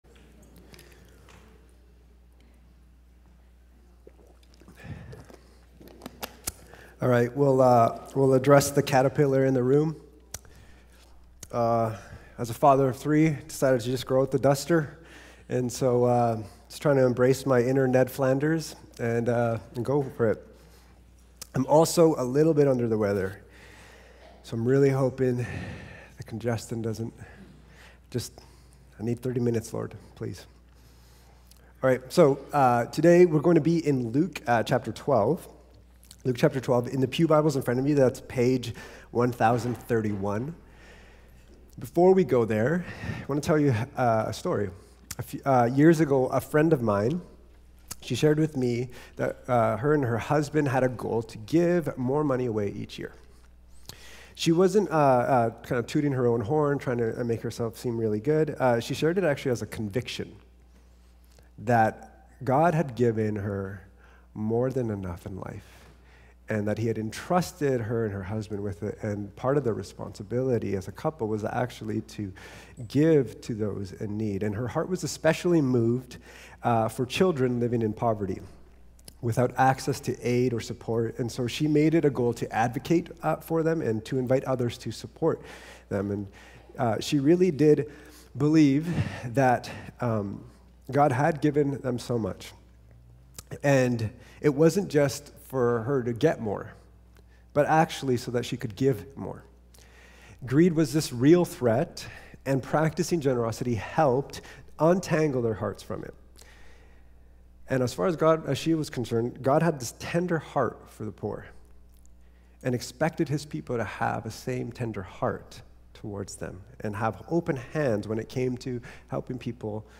Cascades Church Sermons Generosity: The Antidote to Greed Play Episode Pause Episode Mute/Unmute Episode Rewind 10 Seconds 1x Fast Forward 30 seconds 00:00 / 32:05 Subscribe Share Apple Podcasts RSS Feed Share Link Embed